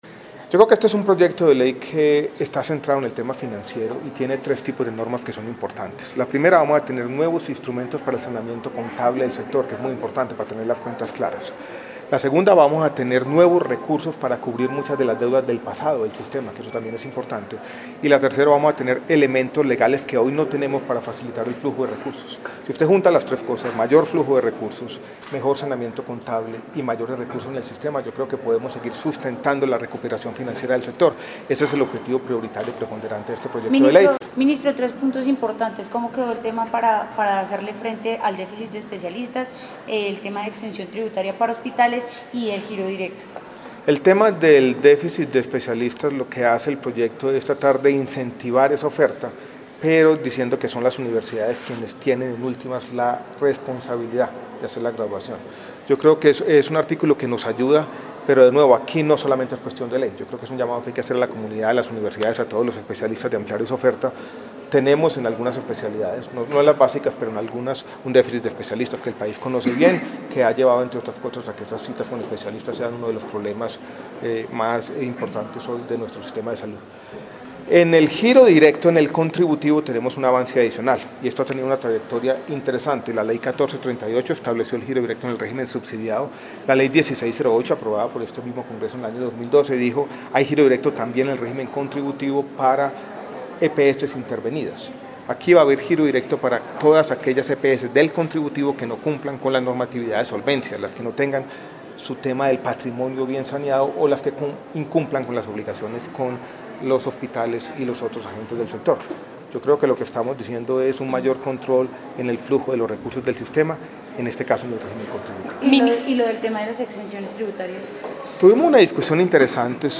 Ministro de Salud y Protección Social, Alejandro Gaviria Uribe
Audio: MinSalud explica los alcances del Proyecto de Ley